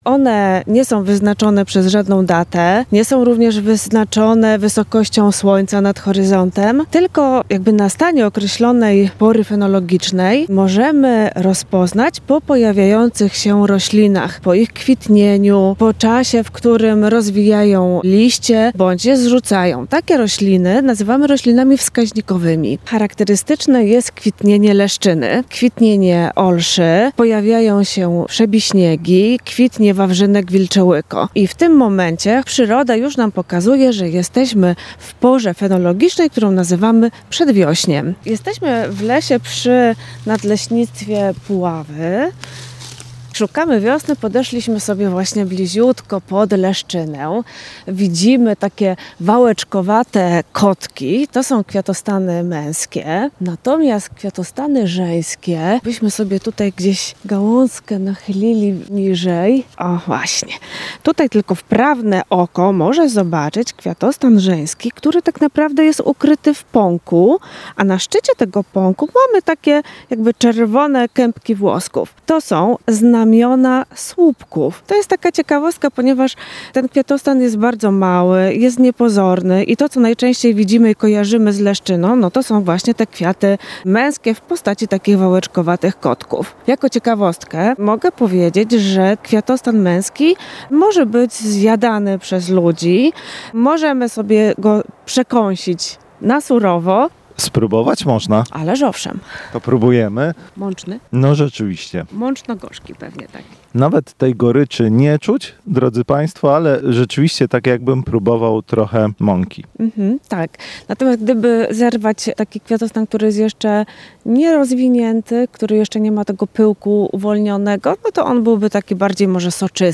Kilkanaście stopni powyżej zera za oknami i bezchmurne niebo pokazują, że wiosna na Lubelszczyznę puka głośno i wyraźnie. Nasz reporter postanowił sprawdzić, czy przyroda także zwiastuje nadejście tej pory roku.